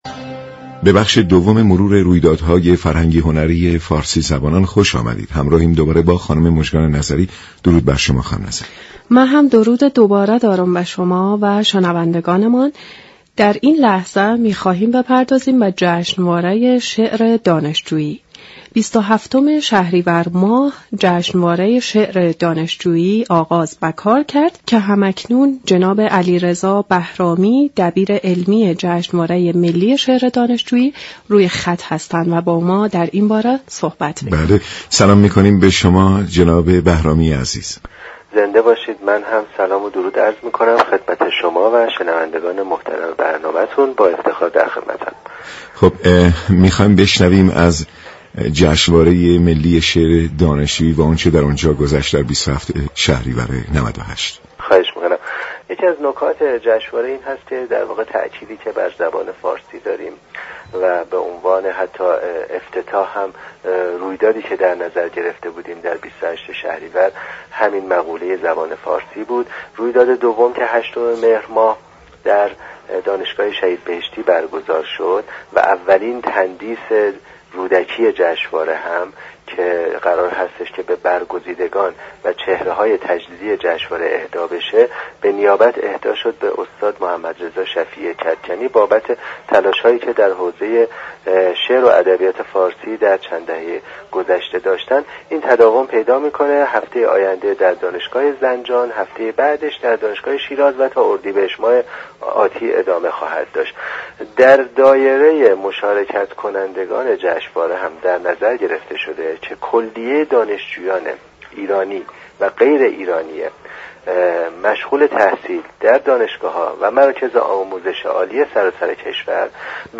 گفت و گو
برنامه پارسی گویان جمعه هر هفته ساعت 23:05 از رادیو ایران پخش می شود.